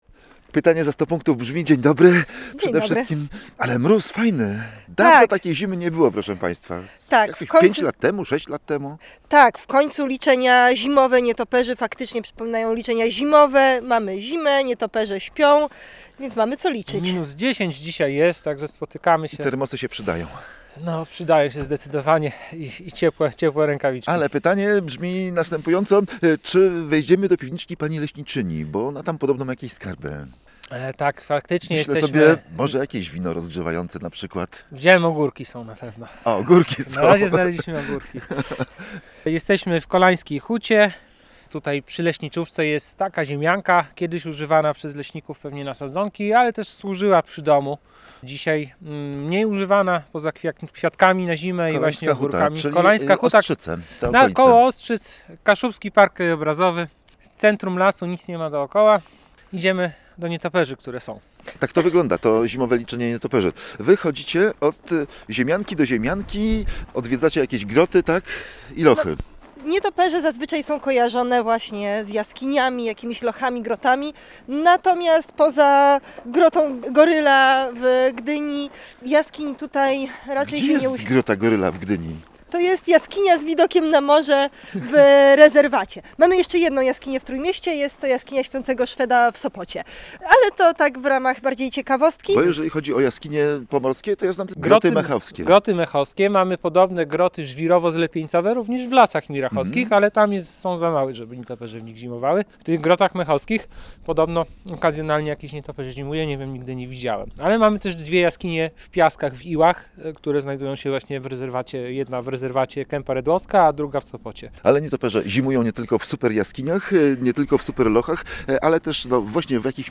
odwiedzamy piwniczkę w Leśnictwie Kolańska Huta i rozmawiamy o liczeniu.